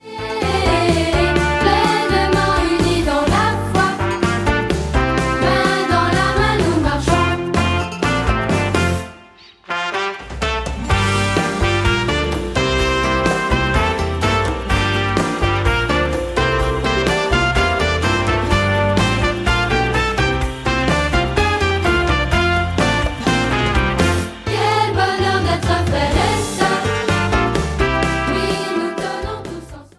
Comédie musicale